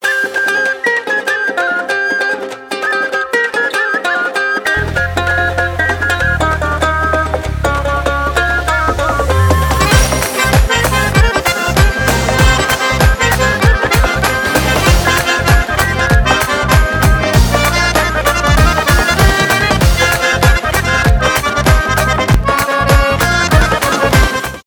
шансон , инструментальные , кавказские , без слов